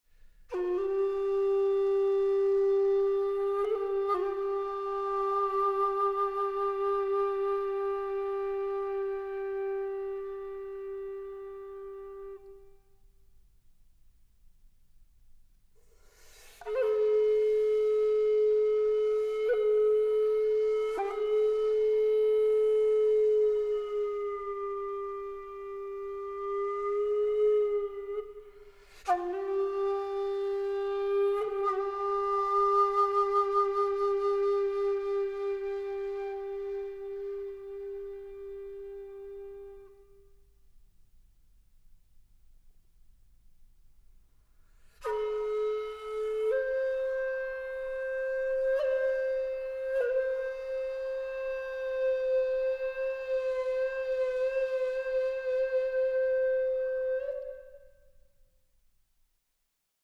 Flöte
Aufnahme: Deutschlandfunk Kammermusiksaal, Köln, 2023 + 2024